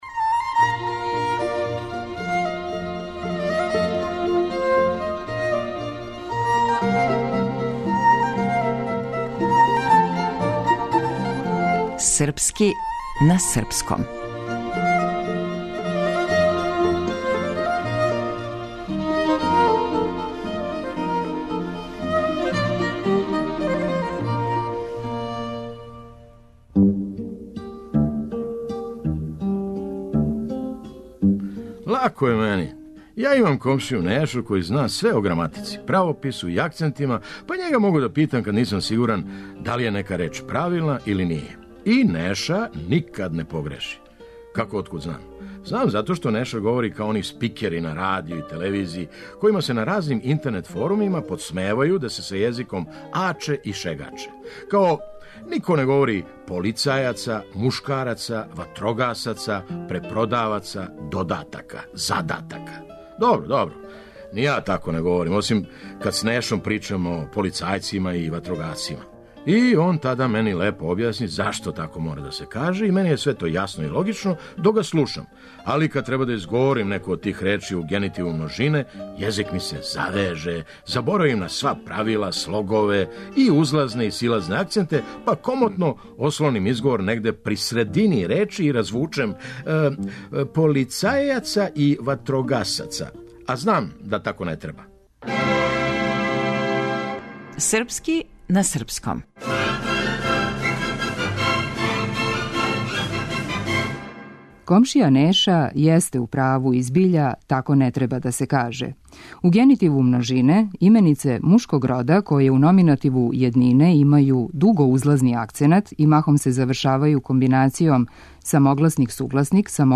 Драмски уметник Феђа Стојановић.